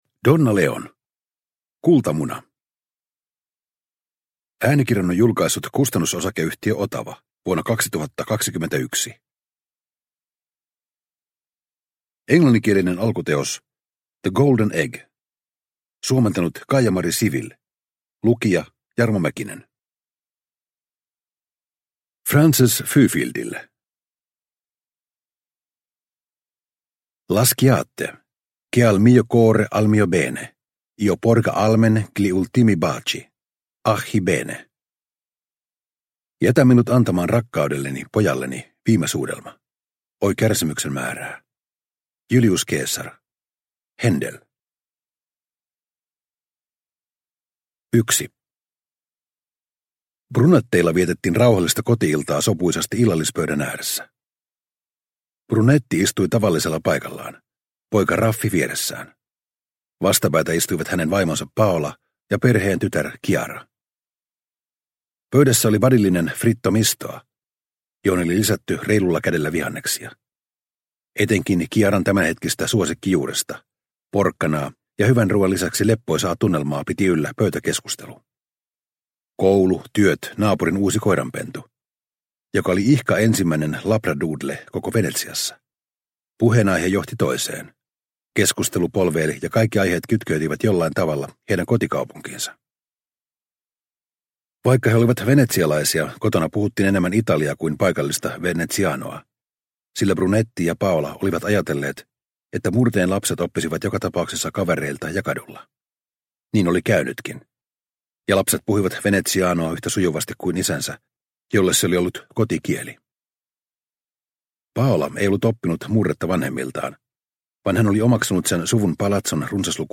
Kultamuna – Ljudbok – Laddas ner